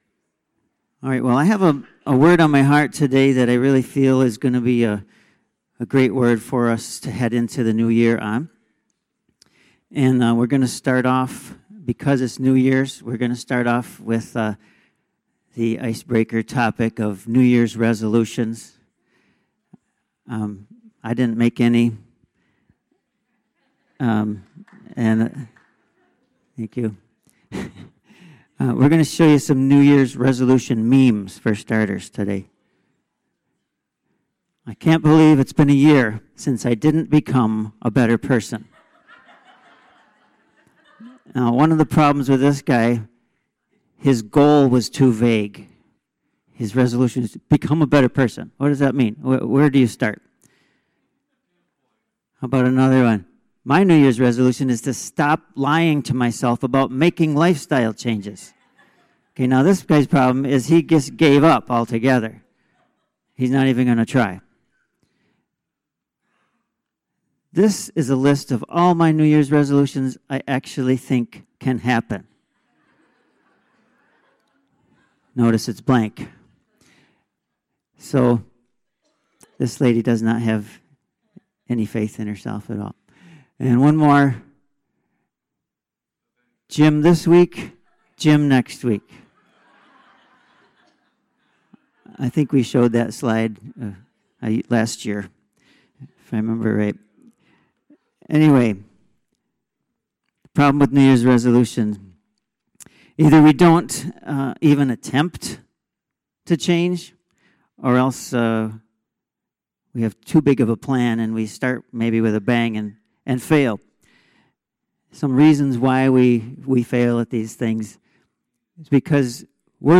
Stand Alone Messages